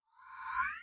tweet_send.ogg